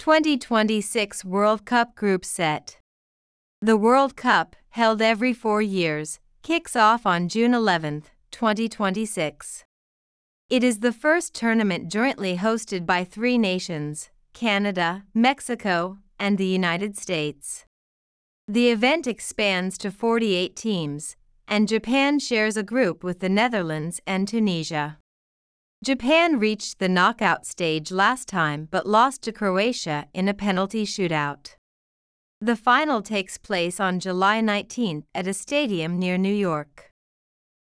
【スロースピード】